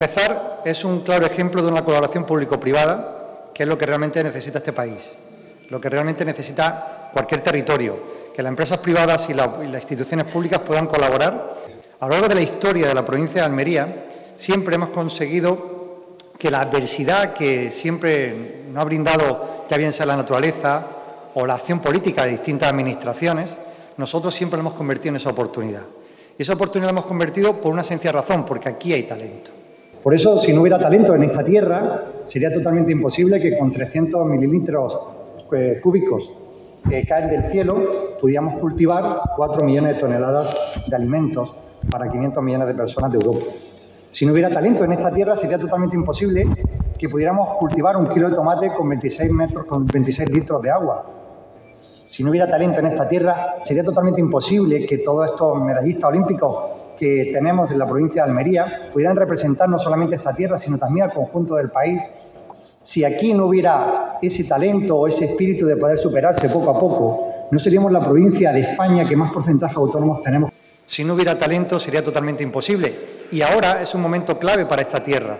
También han intervenido Javier Aureliano García, presidente de la Diputación Provincial de Almería, y Francisco Alonso, delegado de Desarrollo Educativo y Formación Profesional de la Junta de Andalucía, junto a representantes de otras instituciones y patrocinadores del Tour del talento en Almería.
21-02_tour_talento_pdte._javier_aureliano_garcia.mp3